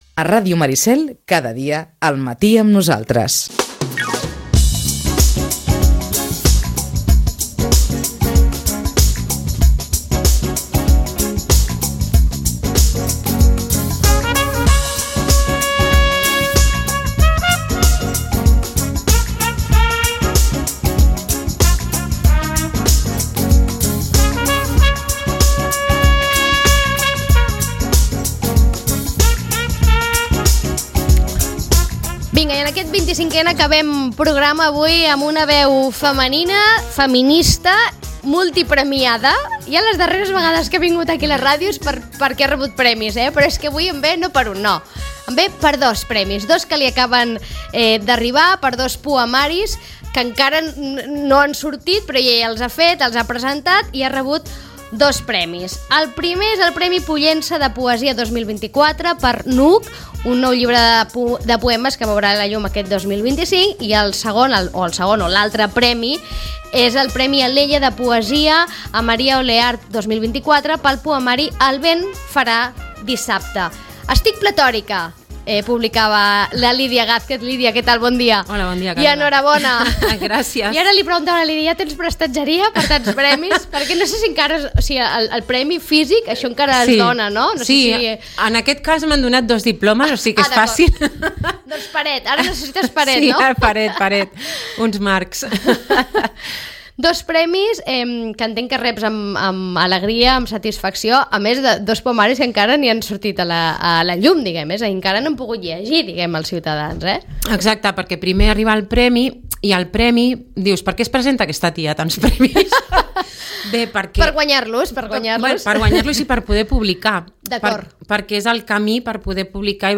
Ràdio Maricel. Emissora municipal de Sitges. 107.8FM. Escolta Sitges.
acabem escoltant-la recitar un poema que encaixa bé amb el missatge del 25N que avui se celebra.